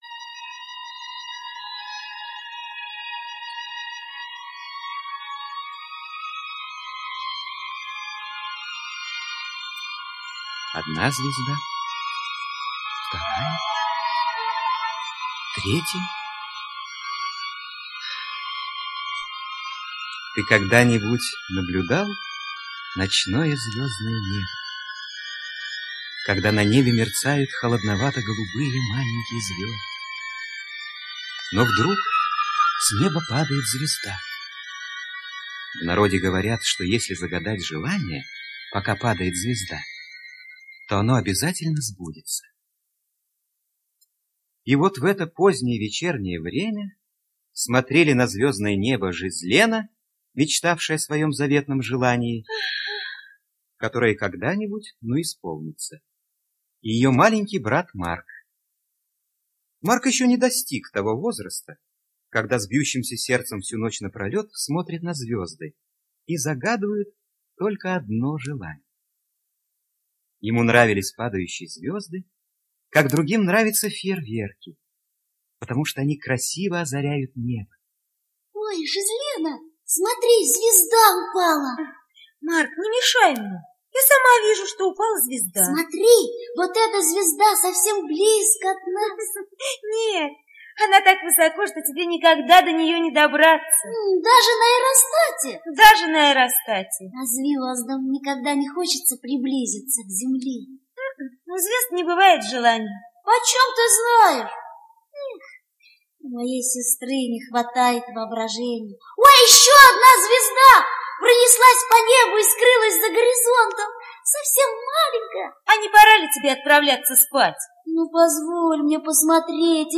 Звезда в клетке - аудиосказка Пино - слушать онлайн